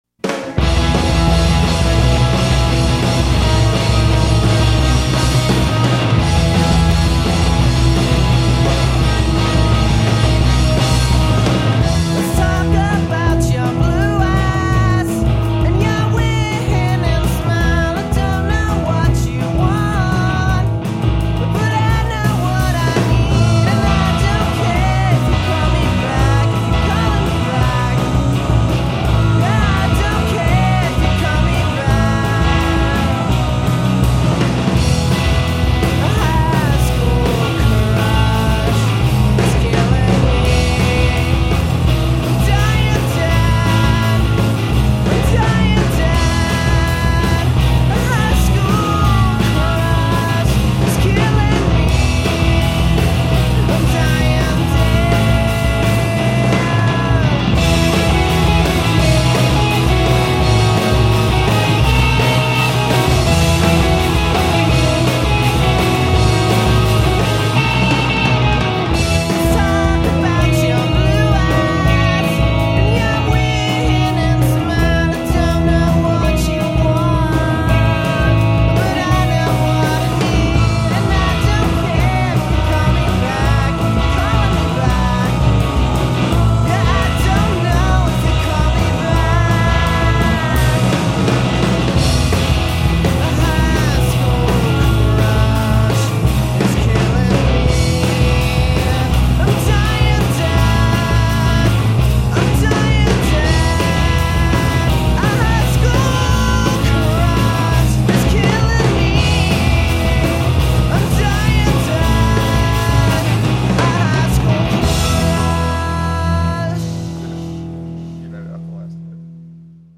guitar, vocals
bass, vocals
drums